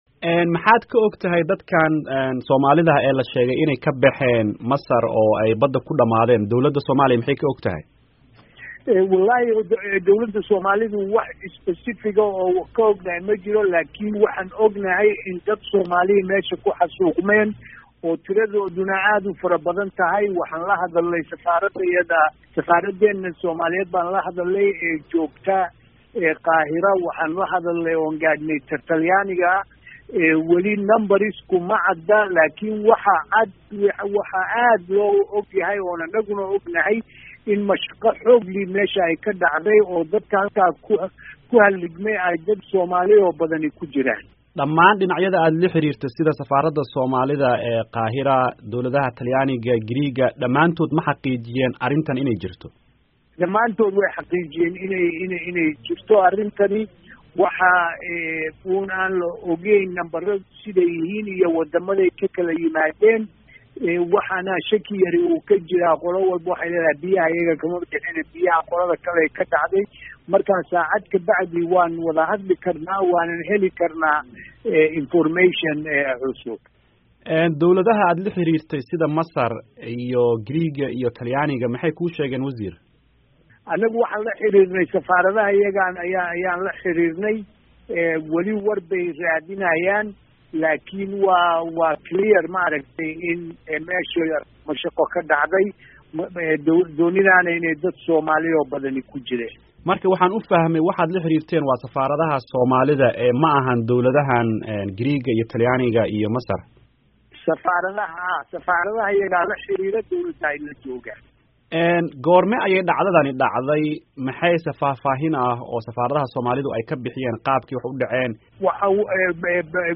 Wareysi: Wasiirka arrimaha dibedda